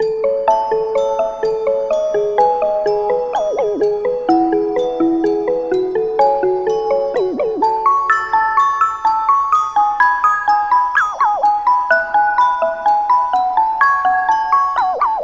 Tag: 126 bpm Trap Loops Bells Loops 2.56 MB wav Key : A FL Studio